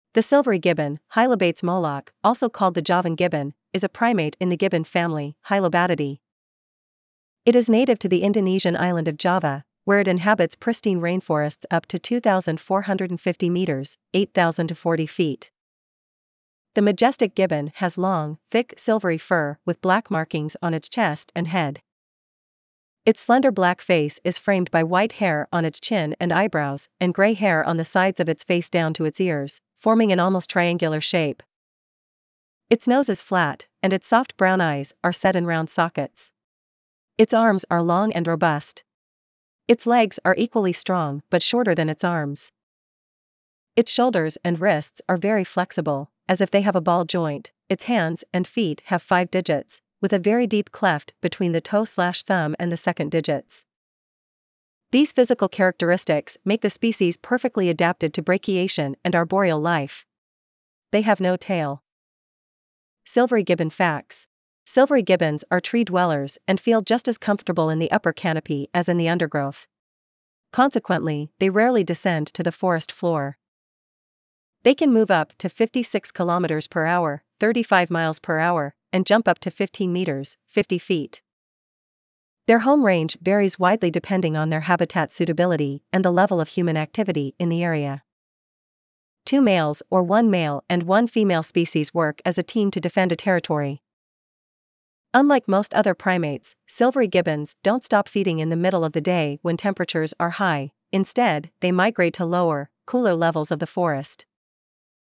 Silvery Gibbon
silvery-gibbon.mp3